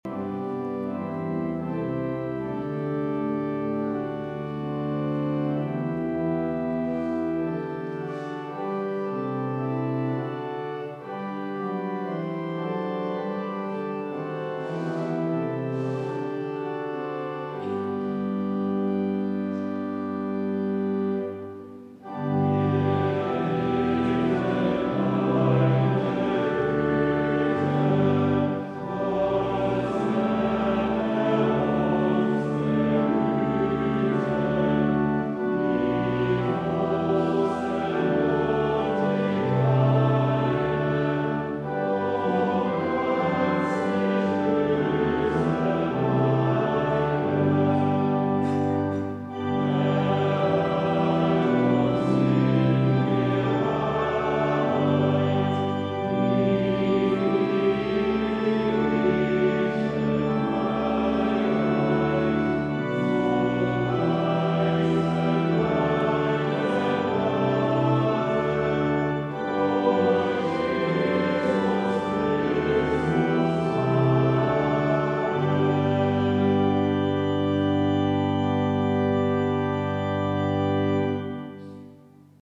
Nun lasst uns Gott dem Herren... (LG 184,7+8) Ev.-Luth. St. Johannesgemeinde Zwickau-Planitz
Audiomitschnitt unseres Gottesdienstes vom 19. Sonntag nach Trinitatis 2025.